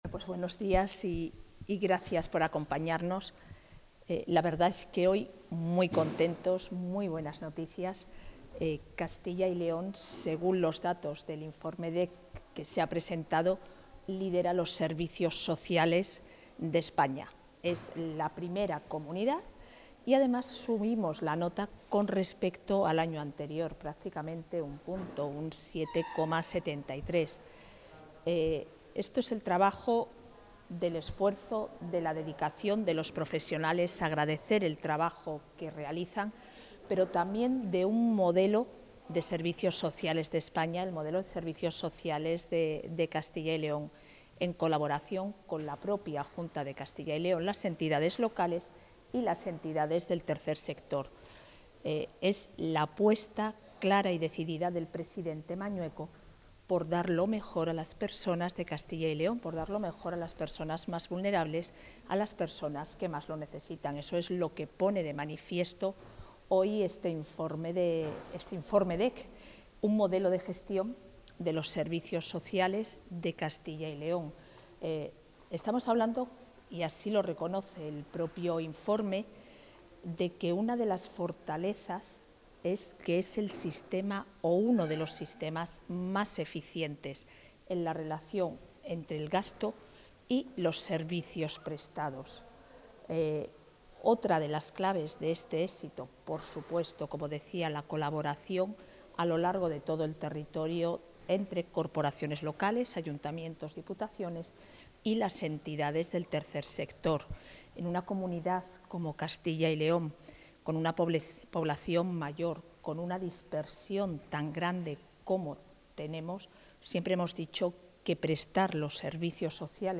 Intervención de la vicepresidenta de la Junta.